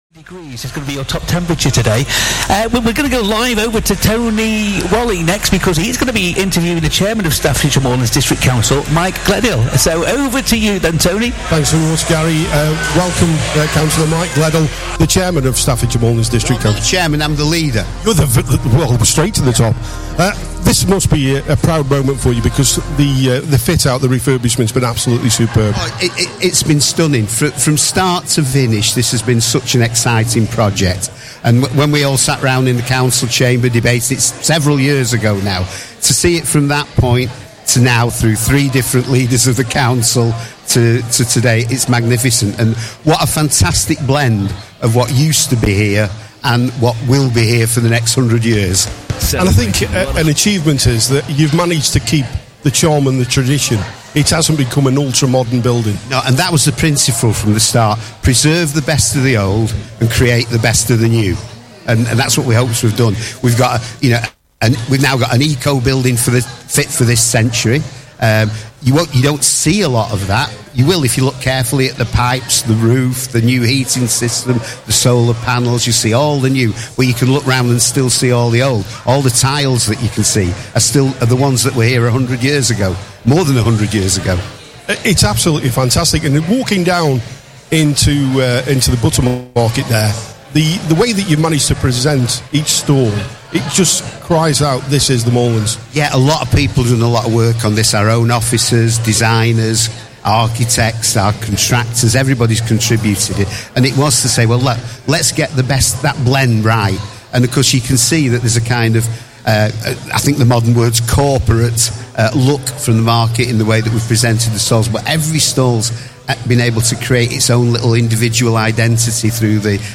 Interview with Councillor Mike Gledhill at Leek Trestle Market